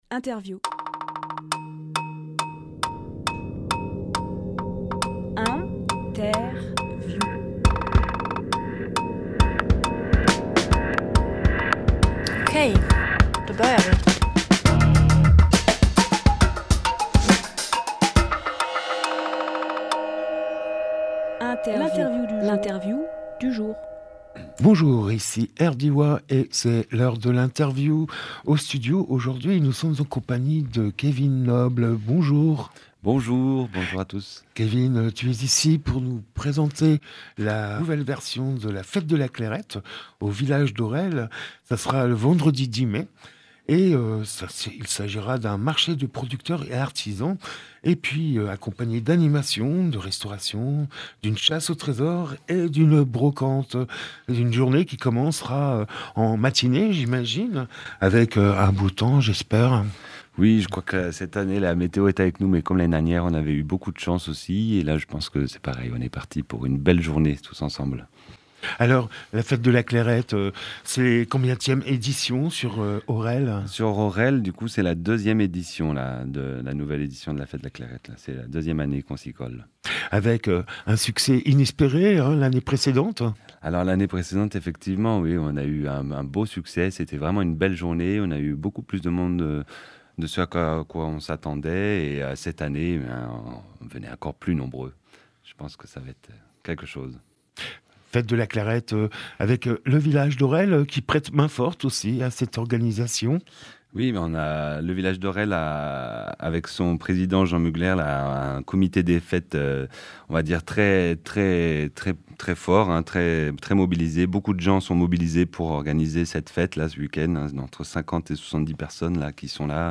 Emission - Interview Fête de la Clairette au village d’Aurel Publié le 3 mai 2024 Partager sur…
Lieu : Studio RDWA